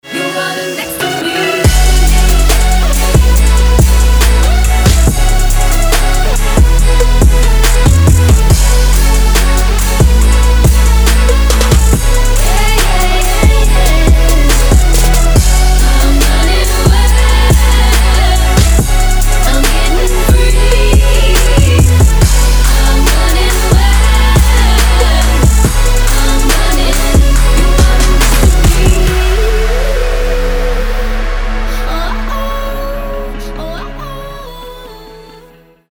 Trap
future bass